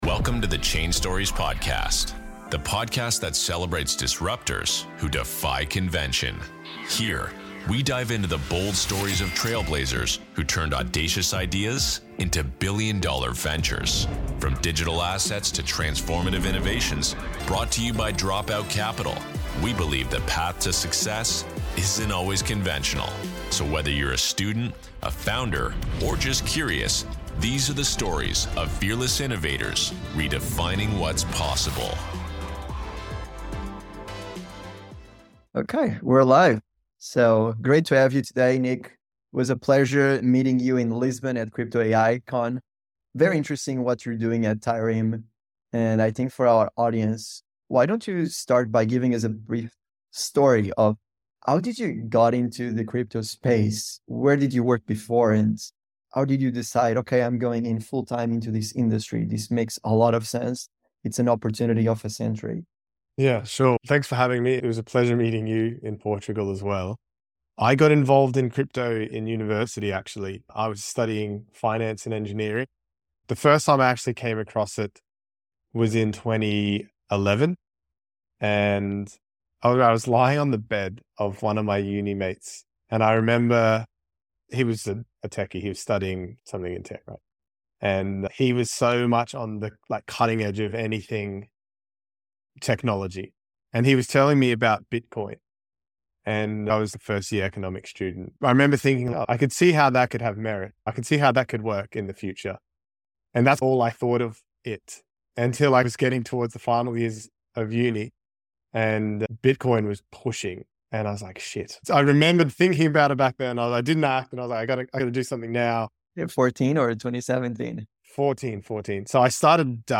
Meme Coins will Dominate this Cycle - Interview with Primary Market Specialist